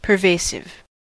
Ääntäminen
IPA : /pəˈveɪ.sɪv/
IPA : /pɚˈveɪ.sɪv/